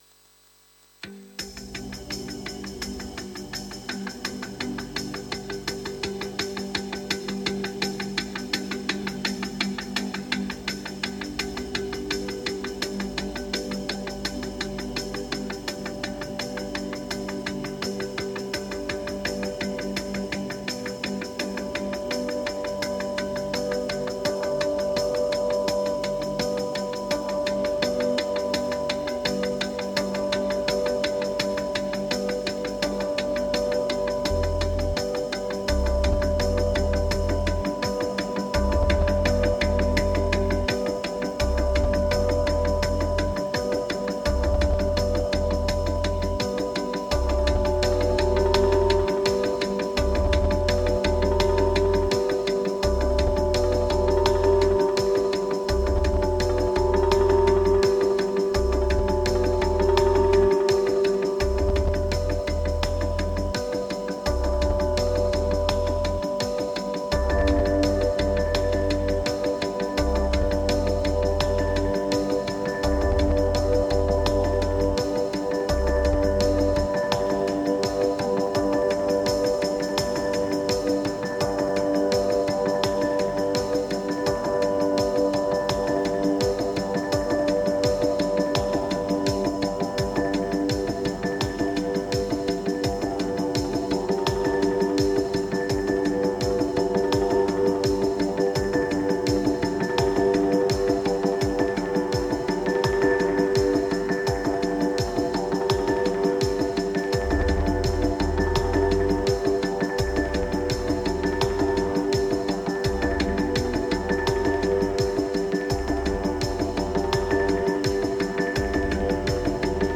1530📈 - 77%🤔 - 84BPM🔊 - 2023-02-09📅 - 459🌟
Ambient Electro Progress Transport Inner Draft Moods